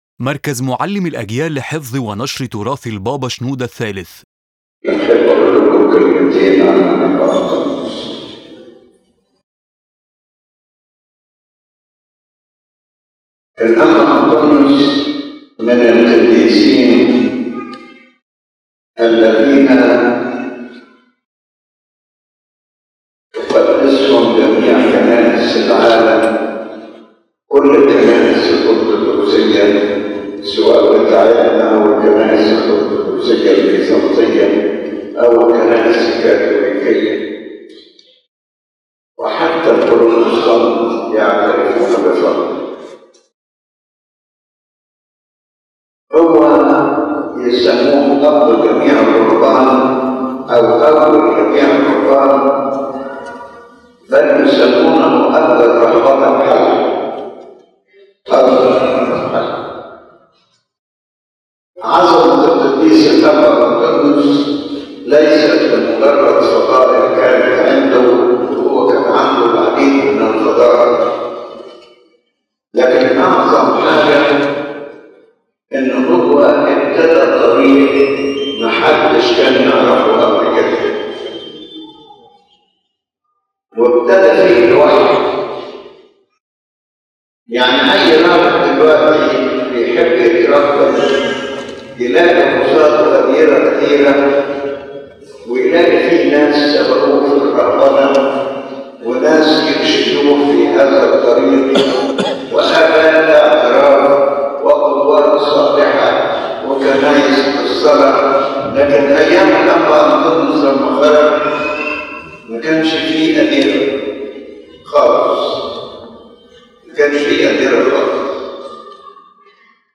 His Holiness Pope Shenouda speaks about the life of Saint Anthony, clarifying that his sanctity is recognized by all Eastern and Western churches and that his status is globally spiritual.